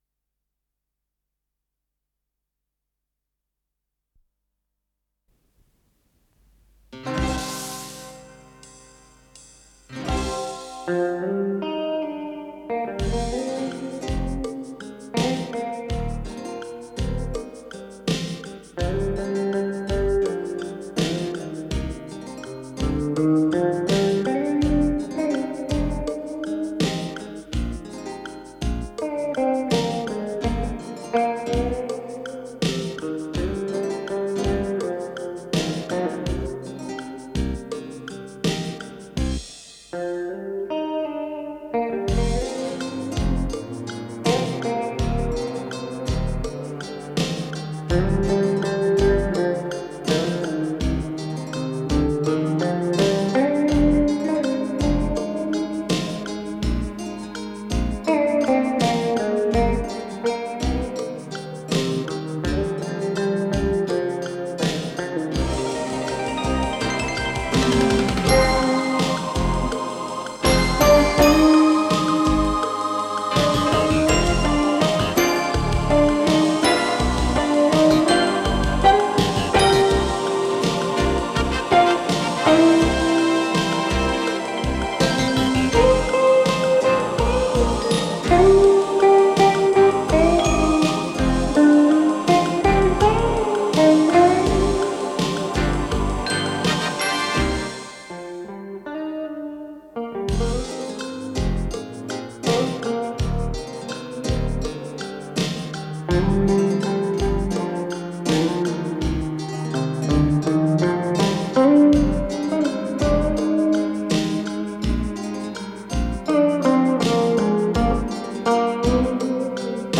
с профессиональной магнитной ленты
запись с наложением
ВариантДубль моно